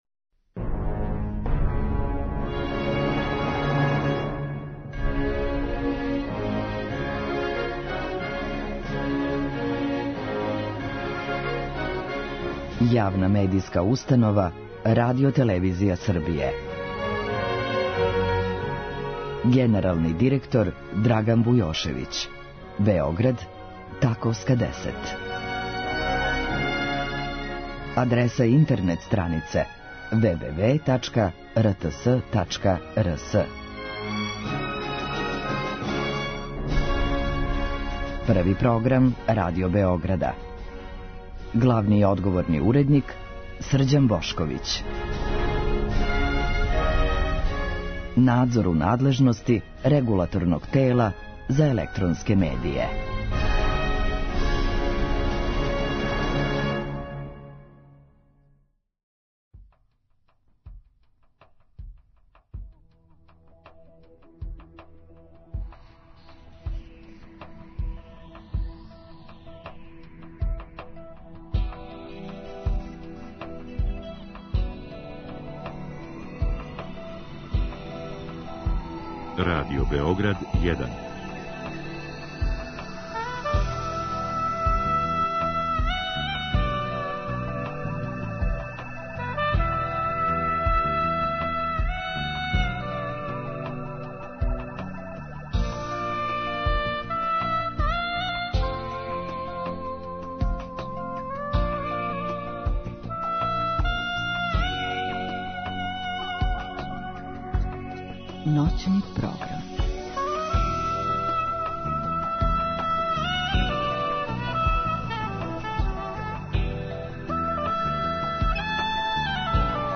Мелодије Косова и Метохије су толико карактеристичне, да их не можемо помешати са музиком другог краја. Ове ноћи говоримо о лепотама Метохије.